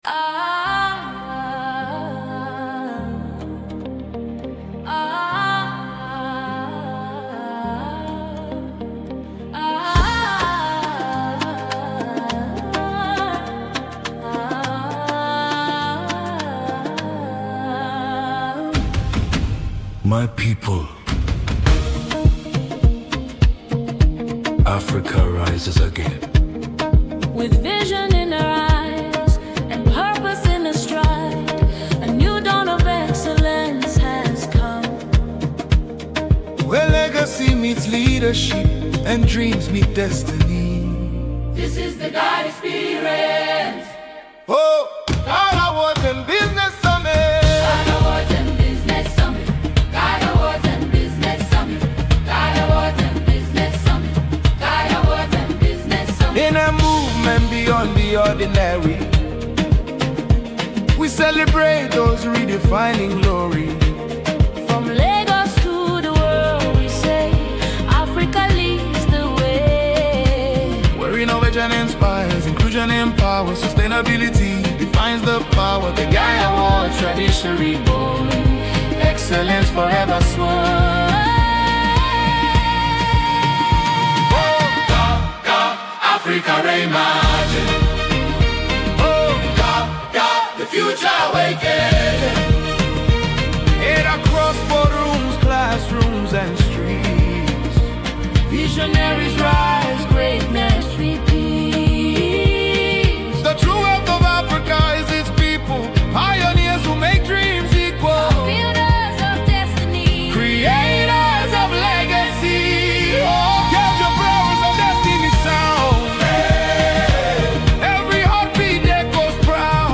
a cinematic celebration of excellence.